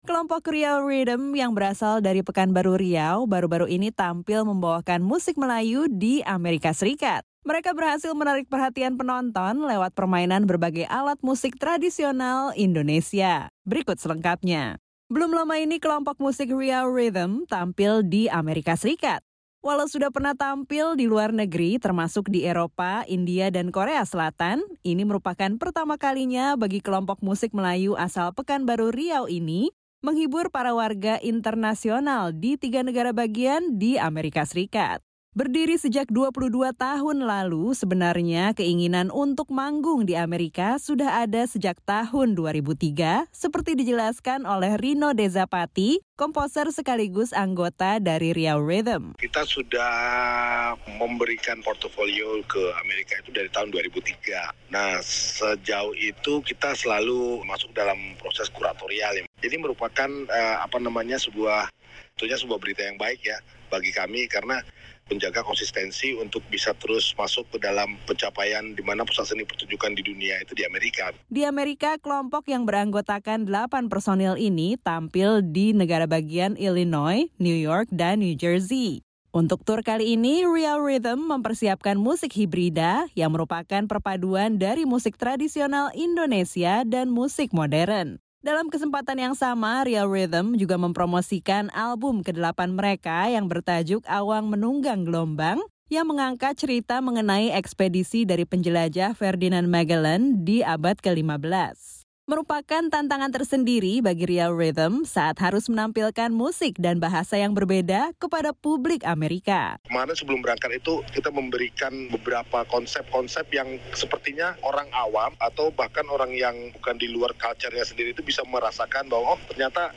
Untuk tur ke Amerika kali ini, Riau Rhythm membawa konsep musik hibrida yang unik, yang merupakan musik tradisional Indonesia yang dipadukan dengan nuansa music modern Amerika.
Riau Rhythm menyajikan musik melayu dengan alat-alat musik tradisional yang mereka bawa langsung dari Indonesia (dok: Riau Rhythm)
Mereka juga membawa biola yang dimaikan dengan gaya melayu, serta gambang, alat tradisional yang berasal dari abad ke-15 dan kerap “dipakai setiap ada upacara.”